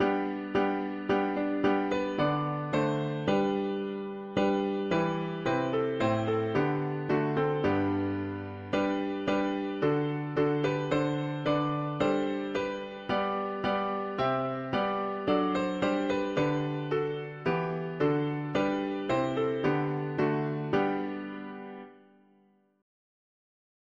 Key: G major